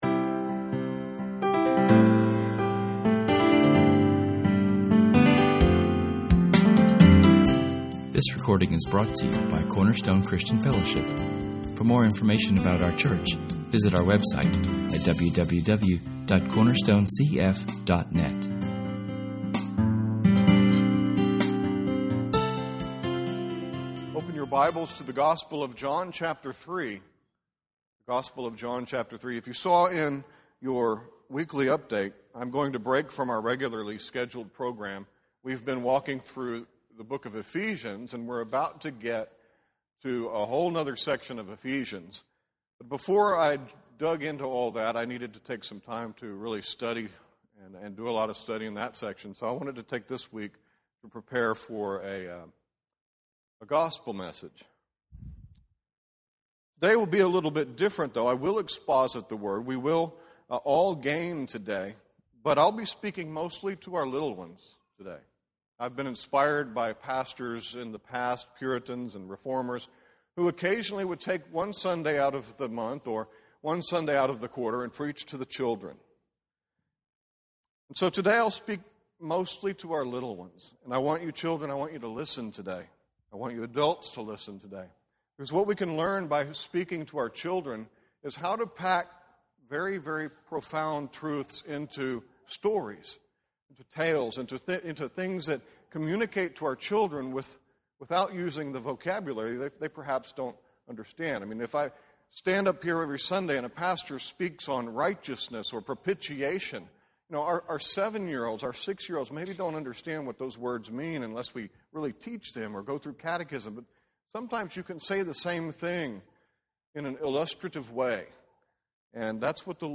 Inspired by Pastor Richard Newton’s series of sermons for children found in his many books, this message is directed to the younger crowd, but not dumbed down.